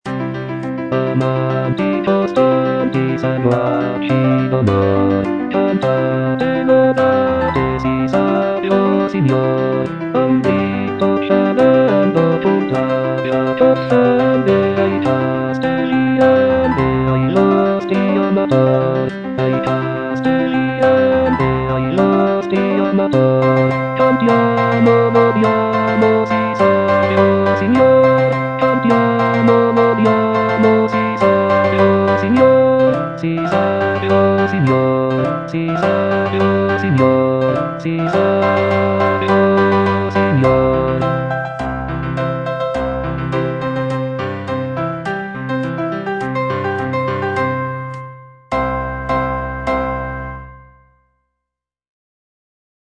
W.A. MOZART - CHOIRS FROM "LE NOZZE DI FIGARO" KV492 Amanti costanti, seguaci d'onor - Bass (Voice with metronome) Ads stop: